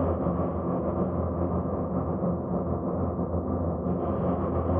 SS_CreepVoxLoopA-04.wav